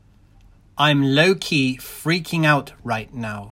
（皆さんが英語の発音練習が出来るように、僕は上の文章を読み上げて、録音致しました。録音したファイルは以下にあります。）